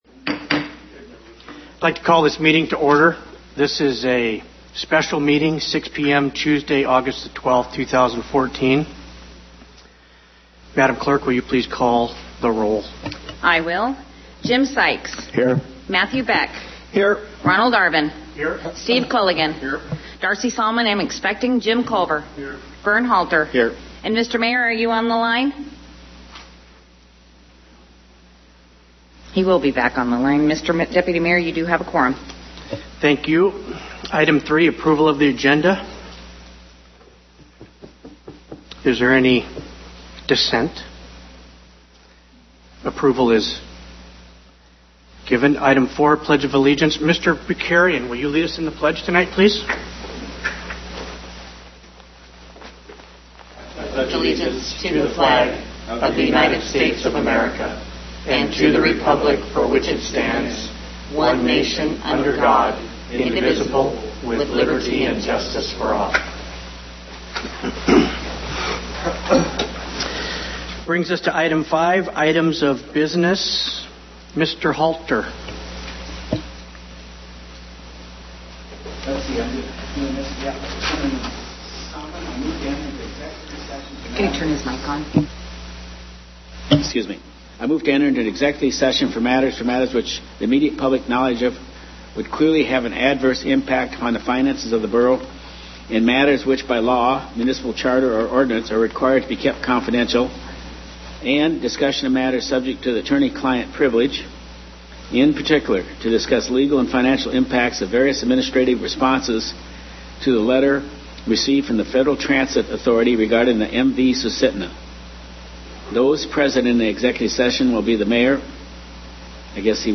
One person spoke under Persons to be heard.